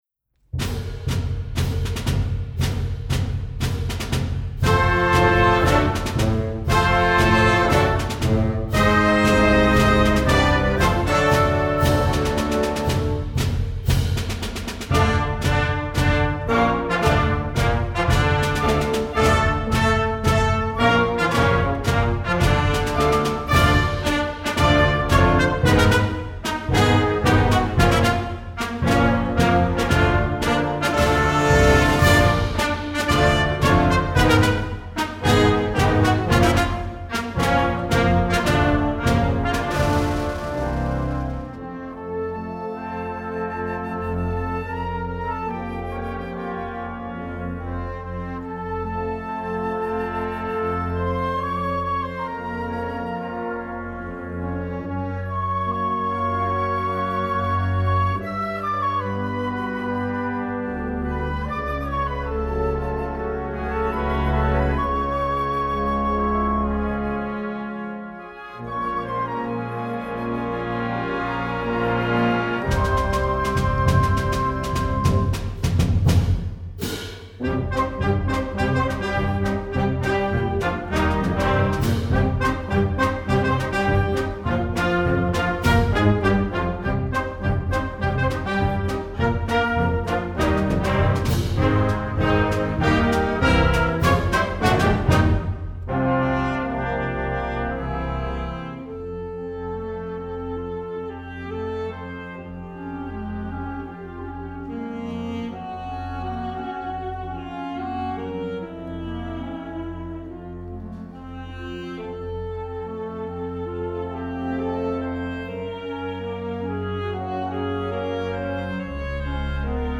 Instrumental Concert Band Movie/TV/Broadway
Concert Band